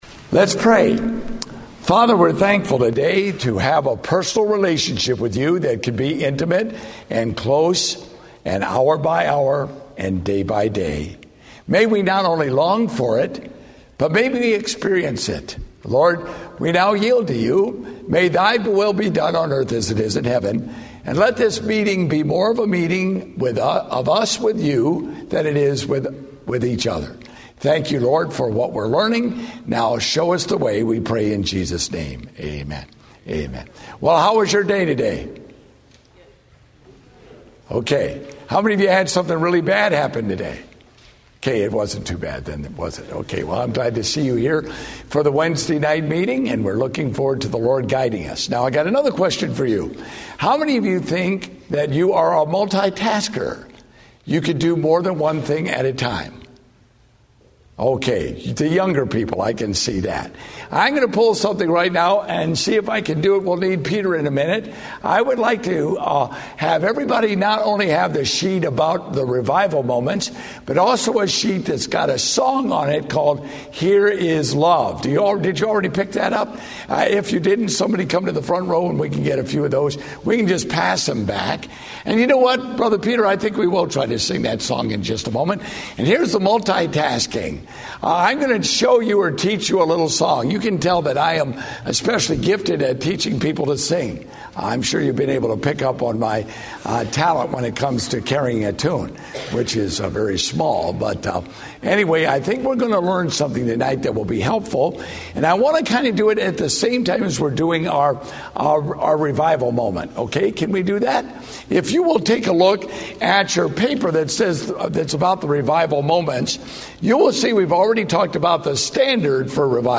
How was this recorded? Date: October 23, 2013 (Revival Meeting)